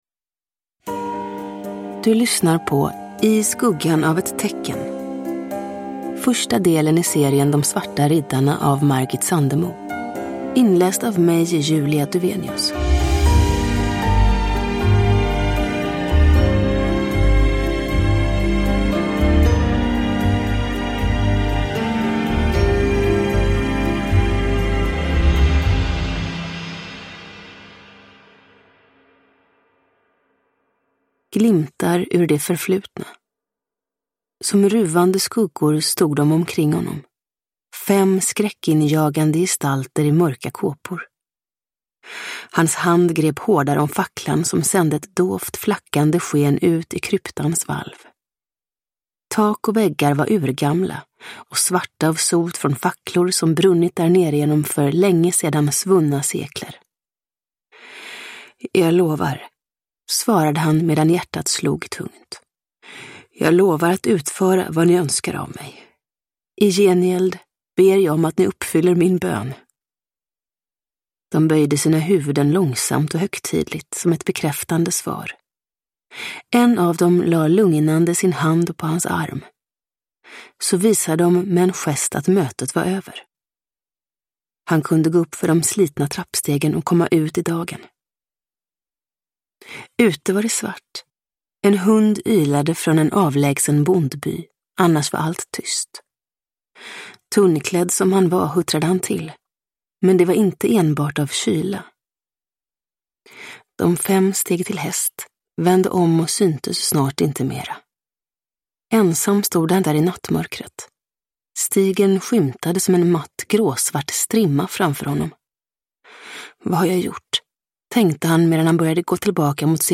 I skuggan av ett tecken – Ljudbok – Laddas ner